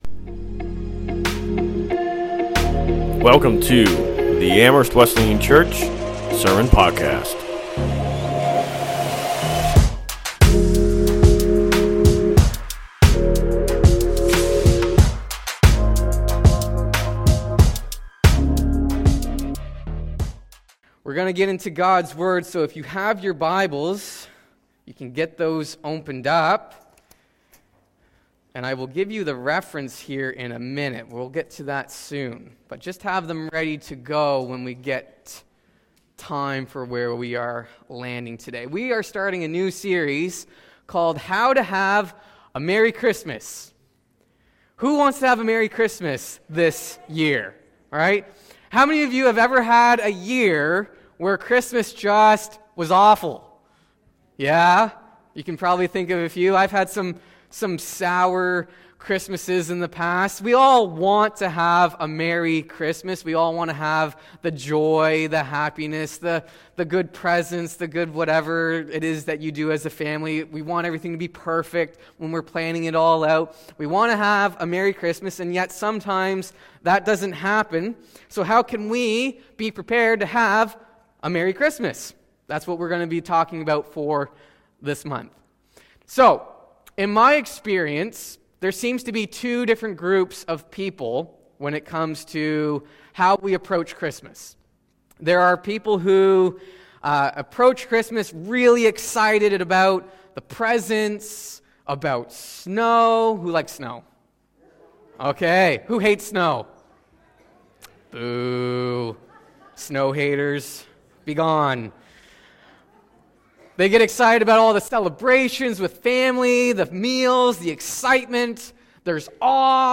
Sermons | Amherst Wesleyan Church